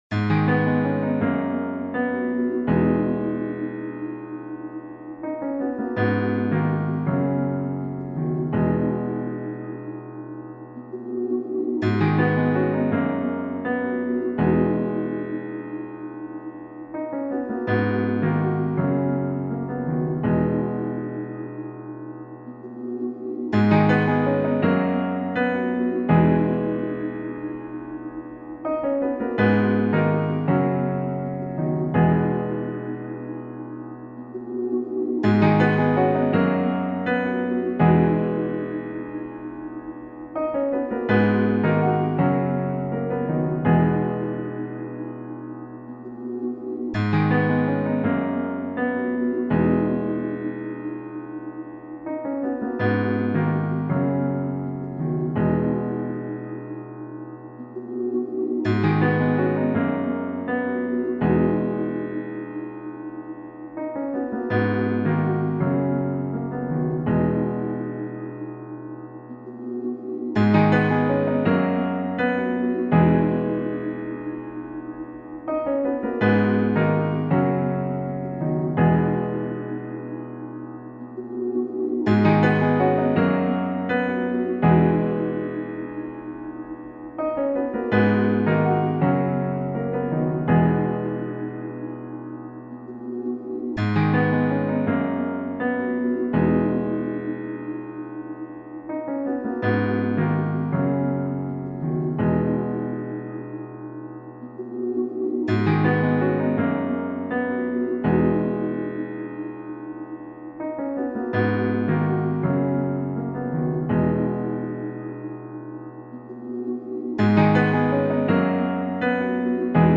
2024 in Hip-Hop Instrumentals , Official Instrumentals